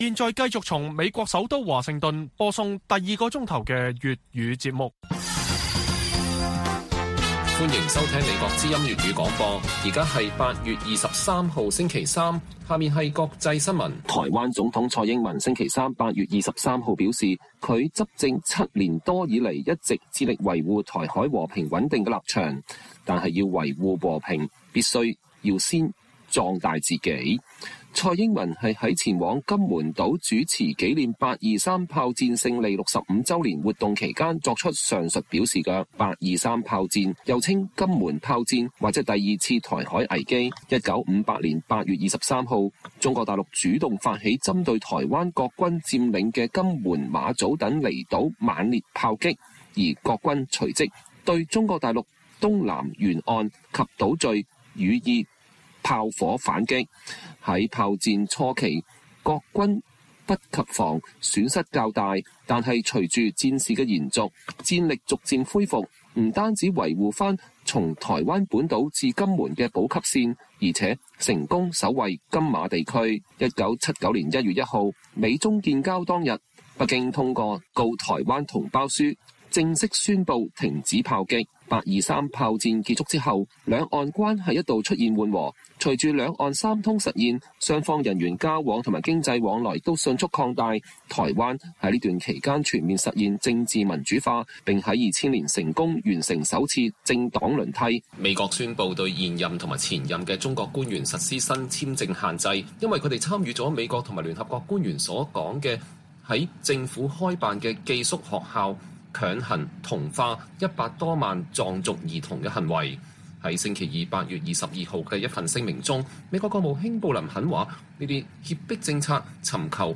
粵語新聞 晚上10-11點: 蔡英文“823”演講指出只有“同島一命”才能守住家園